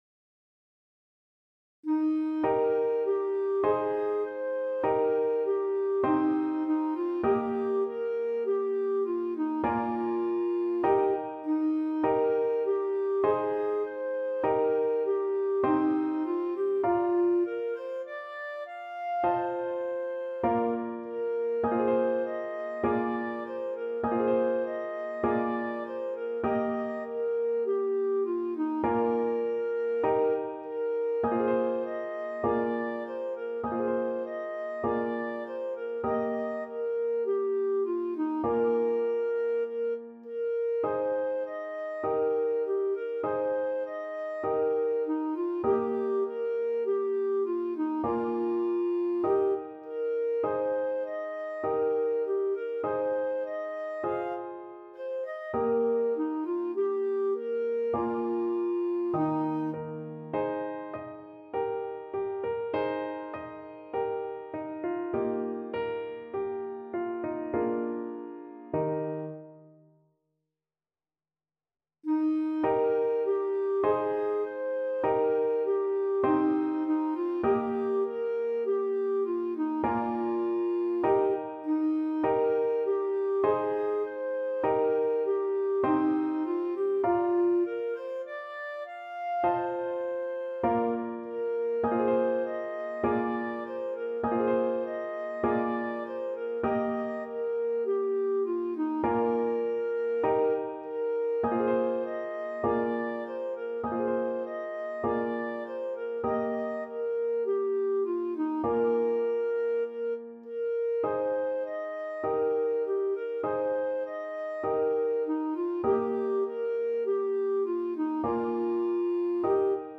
Christmas
Eb5-F6
Moderato
Classical (View more Classical Clarinet Music)